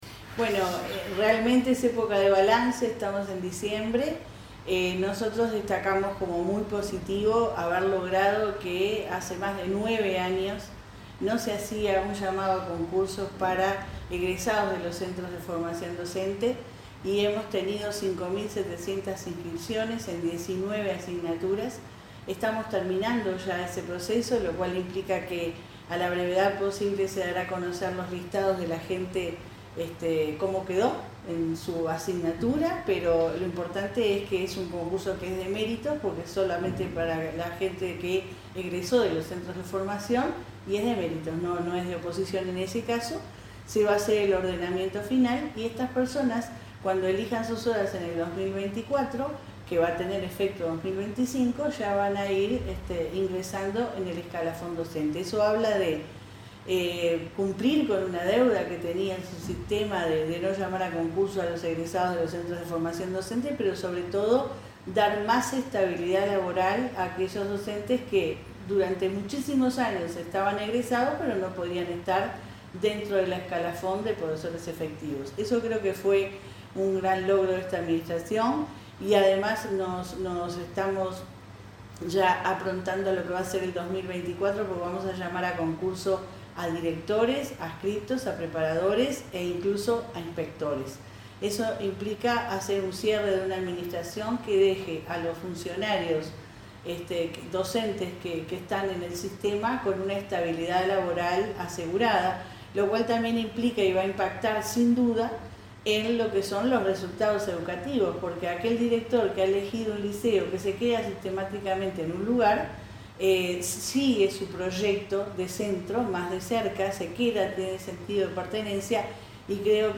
Entrevista a la directora de Educación Secundaria, Jenifer Cherro